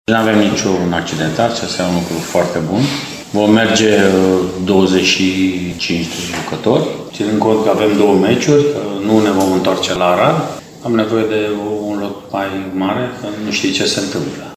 Antrenorul arădenilor a vorbit despre faptul că, în sfârșit, are un lot complet la dispoziție: